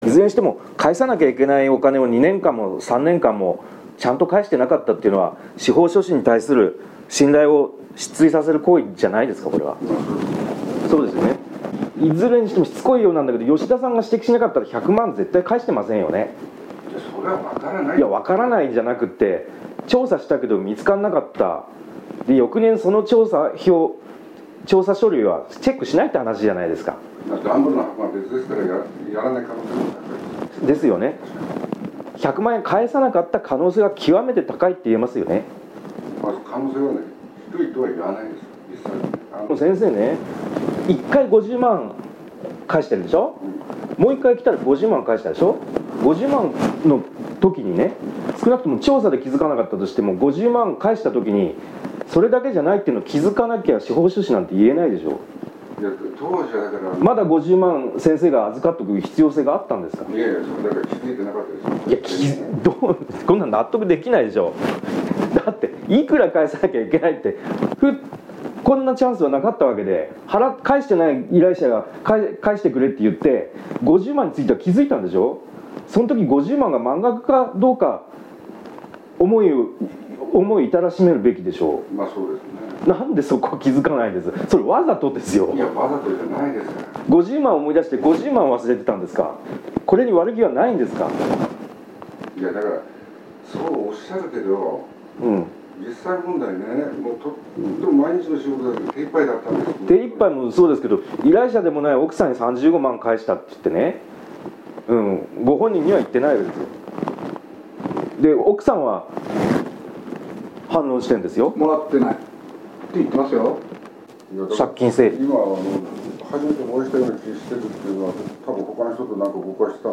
…続きは本誌にて ※取材音声を下記リンクよりお聞きできます。